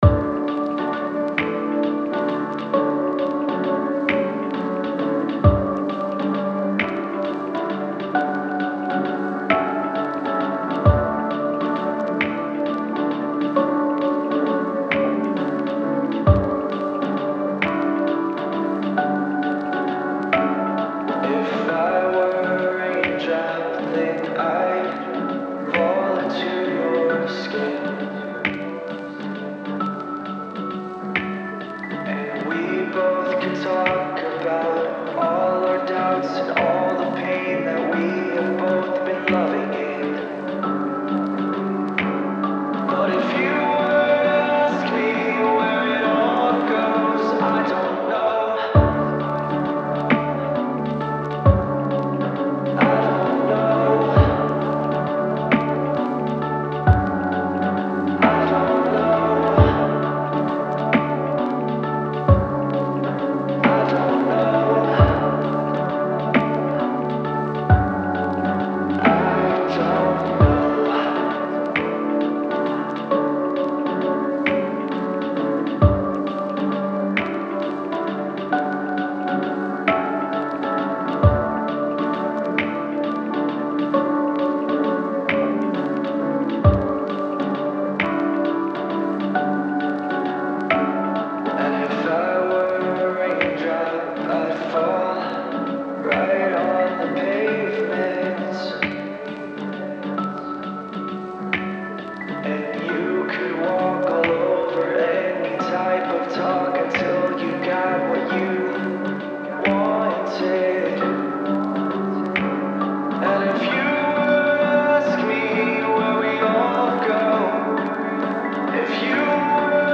A lofi remix that I own for my song titled